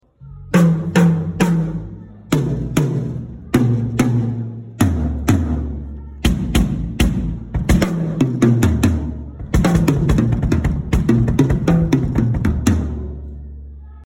Toms kick sound sound effects free download